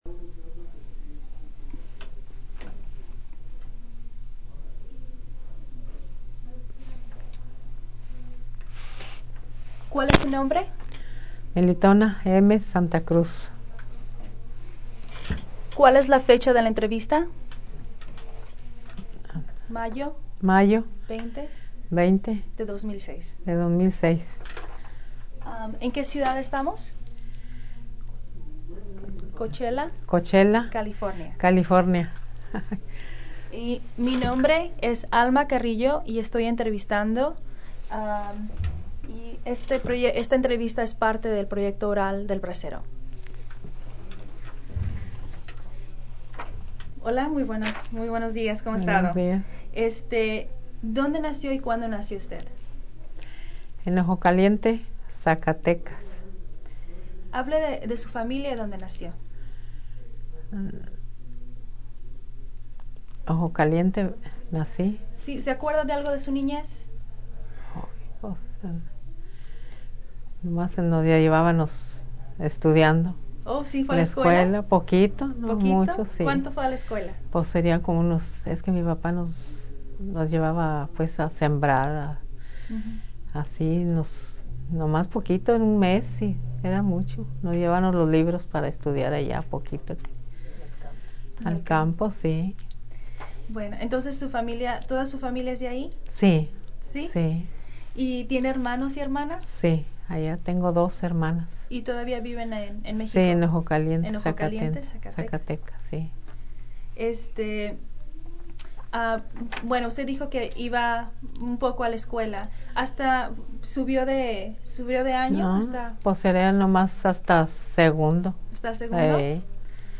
Location Coachella, CA